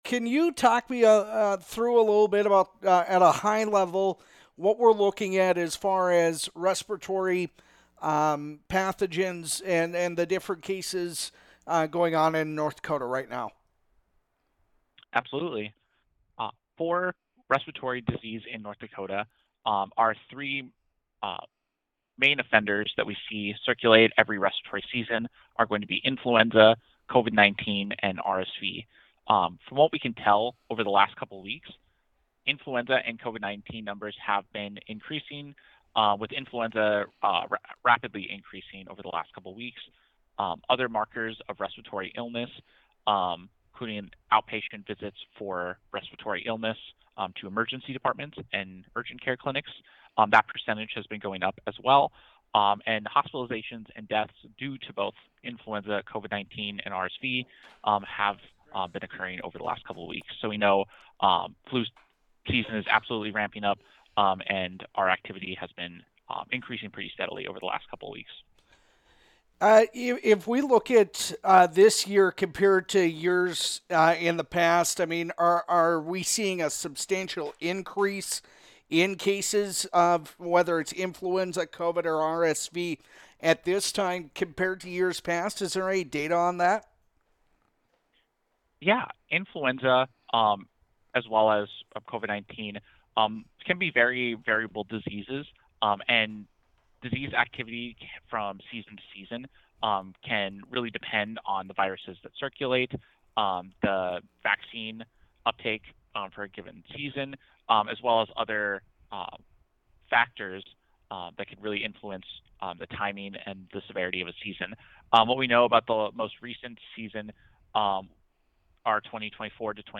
visits with Flag Family News Reporter